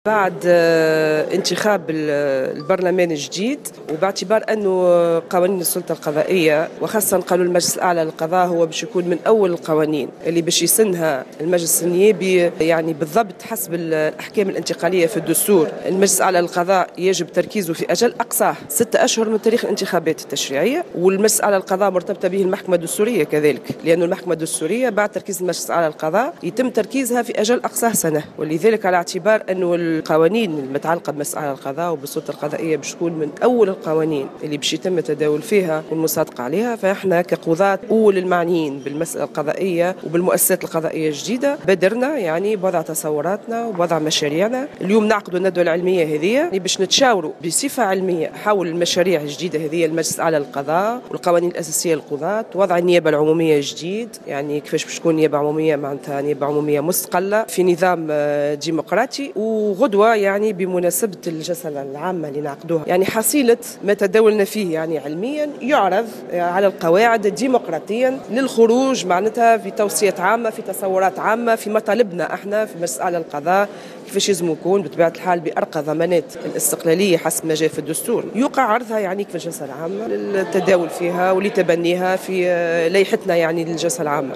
ce samedi 6 décembre 2014 dans une intervention sur les ondes de Jawhara FM